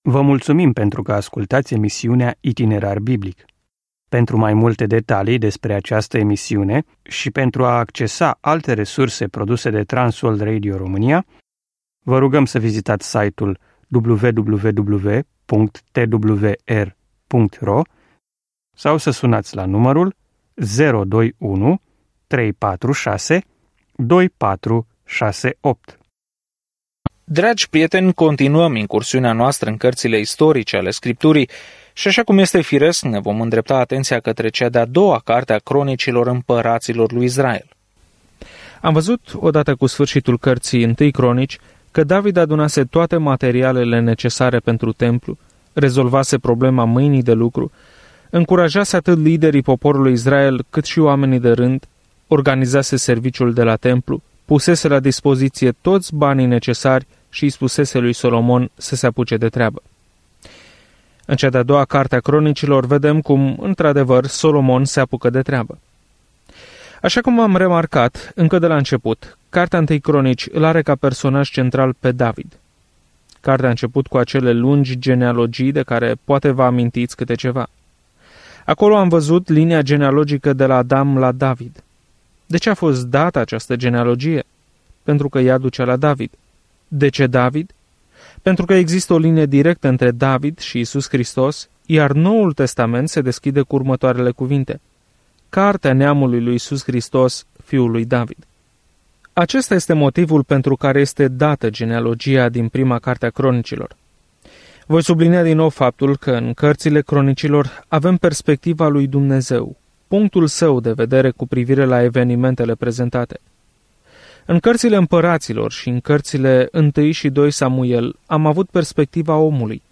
Scriptura 2 Cronici 1 Începe acest plan Ziua 2 Despre acest plan În 2 Cronici, obținem o perspectivă diferită asupra poveștilor pe care le-am auzit despre regii și profeții din trecut ai Israelului. Călătoriți zilnic prin cele 2 Cronici în timp ce ascultați studiul audio și citiți versete selectate din Cuvântul lui Dumnezeu.